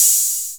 • Loud Open High-Hat B Key 19.wav
Royality free open high-hat tuned to the B note. Loudest frequency: 8362Hz
loud-open-high-hat-b-key-19-HYe.wav